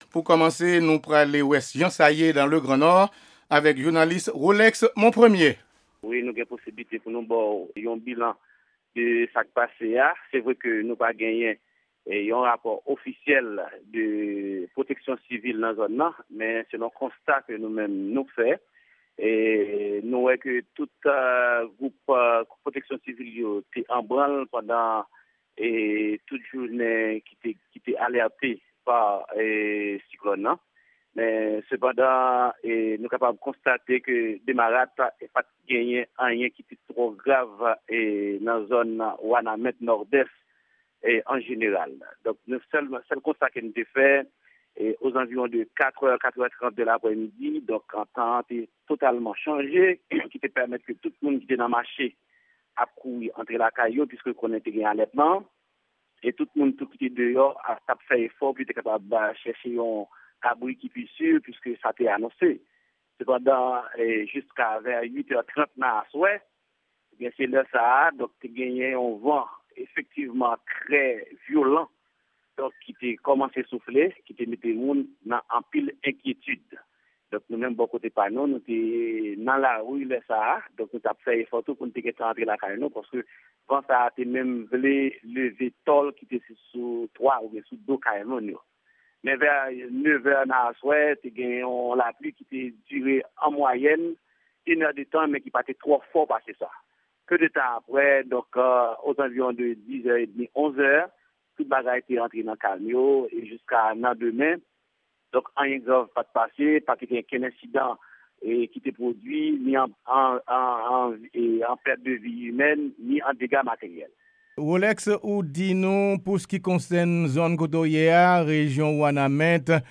Entèvyou sou Pasaj "Tanpèt Erika" nan Gran Nò a ak Depatman Sidès